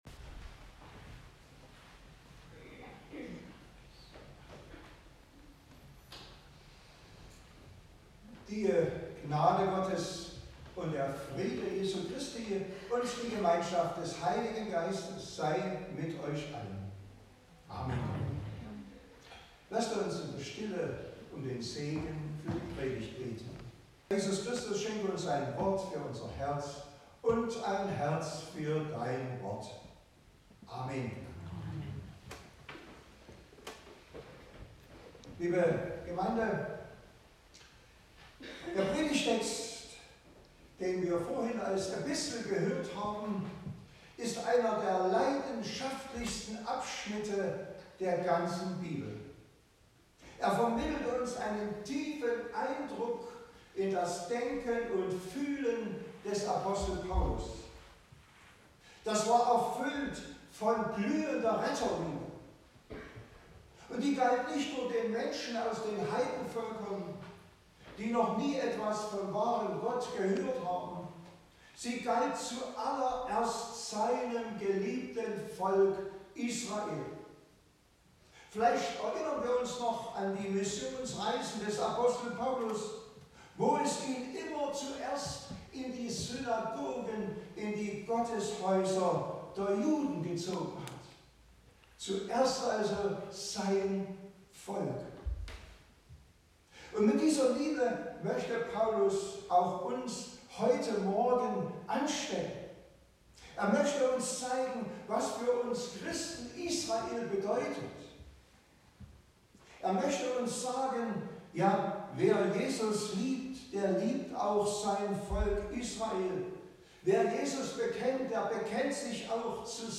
Gottesdienstart: Predigtgottesdienst Wildenau